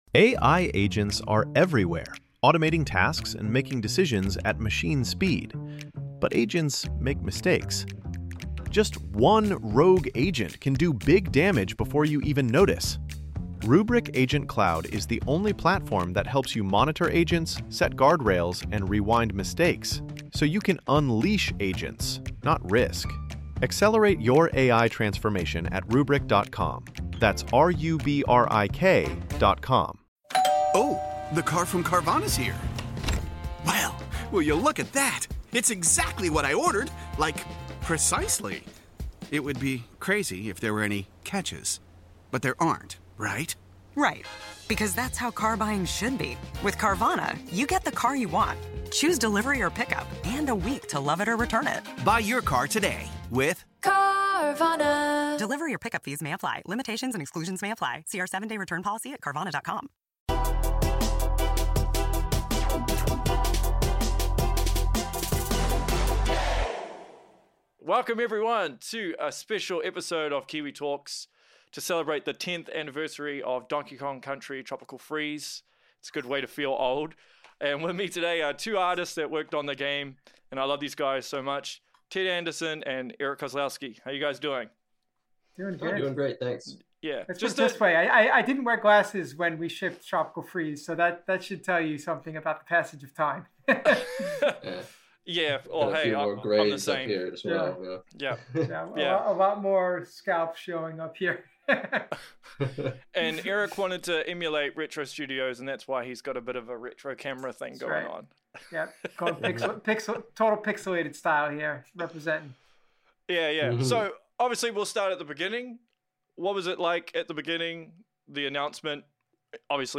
Donkey Kong Country Tropical Freeze 10th Anniversary Interview With Former Retro Studios Devs ~ Kiwi Talkz Podcast